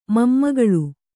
♪ mammagaḷu